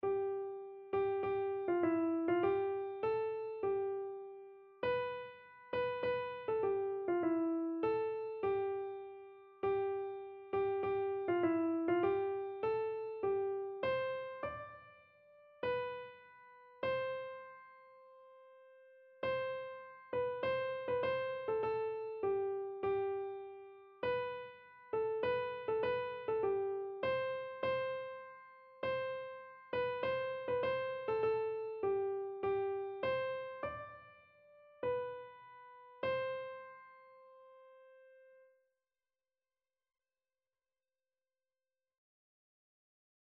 Christian
Free Sheet music for Keyboard (Melody and Chords)
4/4 (View more 4/4 Music)
Keyboard  (View more Easy Keyboard Music)
Classical (View more Classical Keyboard Music)